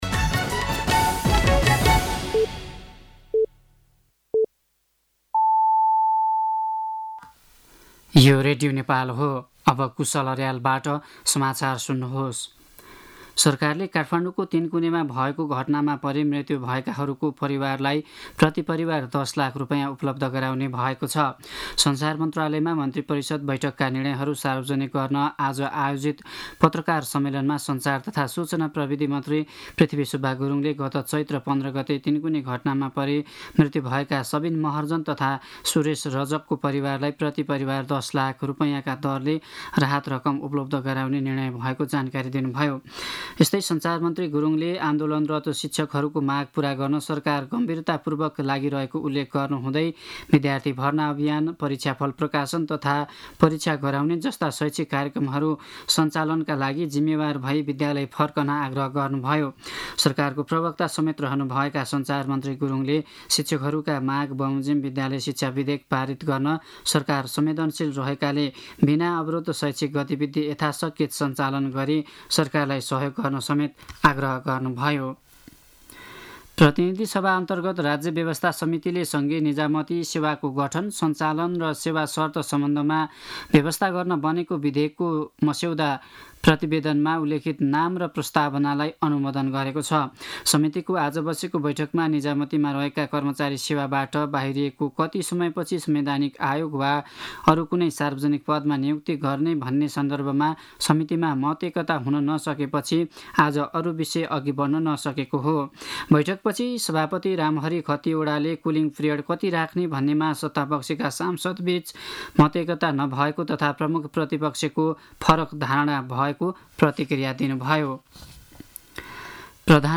साँझ ५ बजेको नेपाली समाचार : १० वैशाख , २०८२
5.-pm-nepali-news-2.mp3